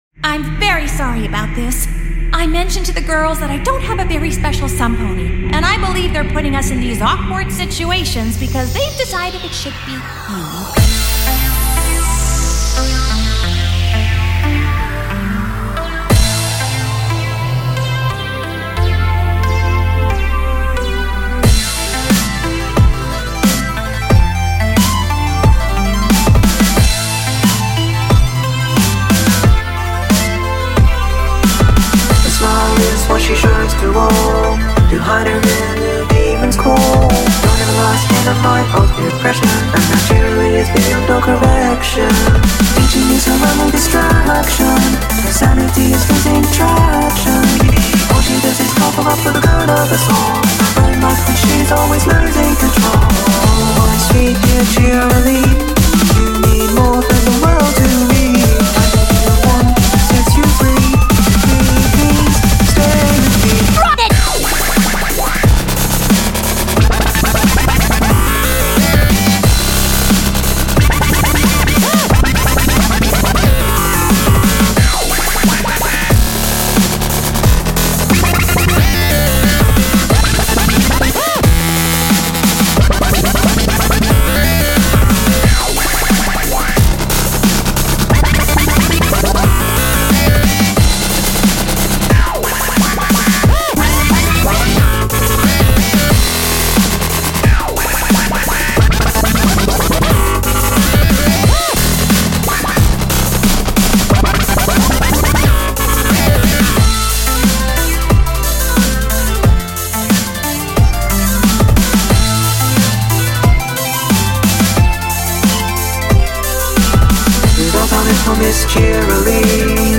That voice sample is something I created.